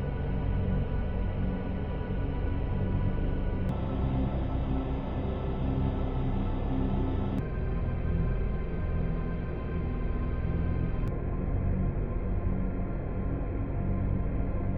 vgm,